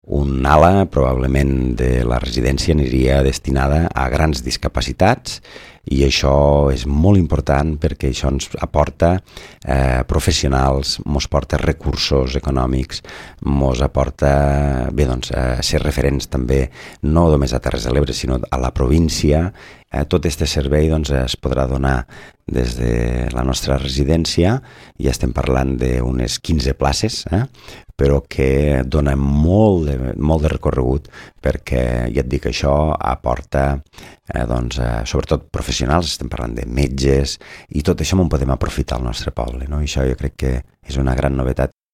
Així ho va donar a conèixer l’alcalde Jordi Gaseni, el passat divendres en una entrevista a La Cala Ràdio.